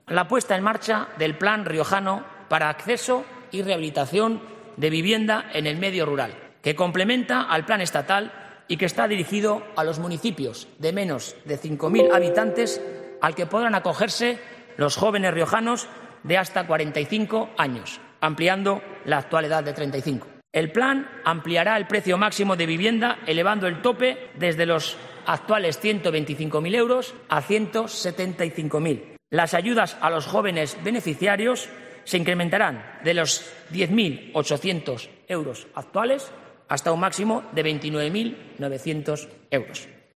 El Parlamento regional ha acogido este mediodía la primera jornada del pleno de investidura del candidato a la presidencia del Gobierno de La Rioja, Gonzalo Capellán.